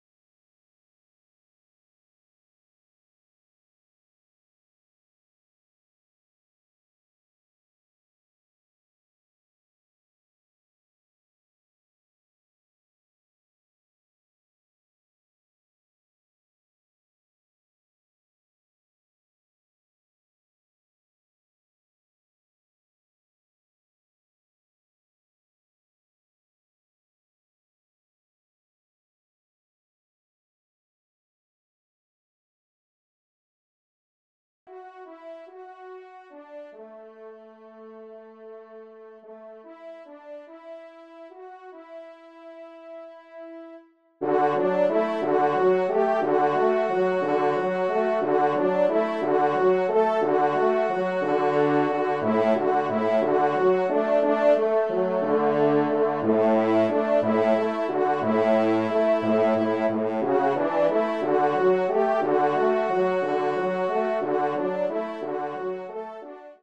3e Trompe     (L’écoute démarre dans 32” de M.20 à M.52)